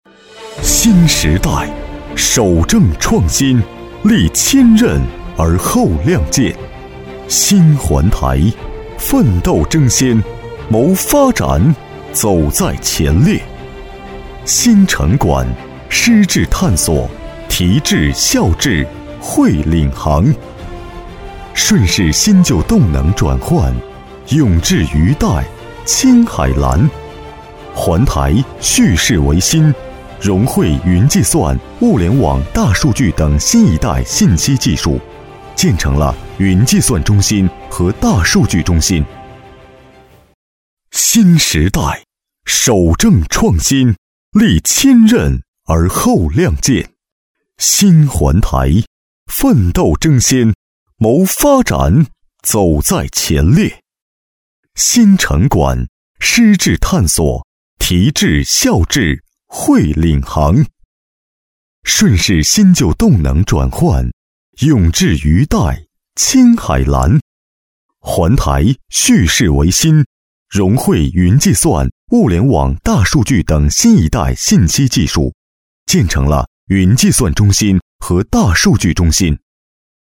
标签： 浑厚
配音风格： 浑厚